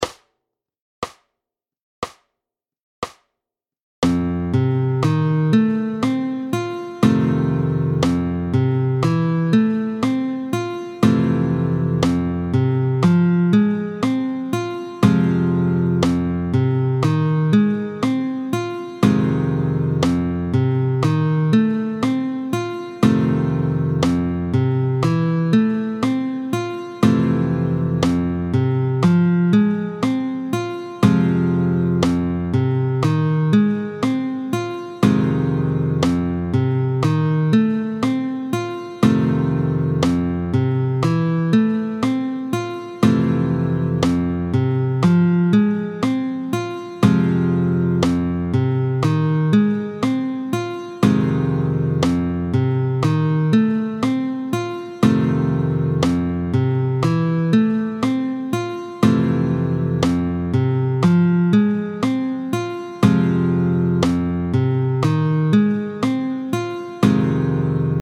28-04 Variations sur la forme de Mi, tempo 60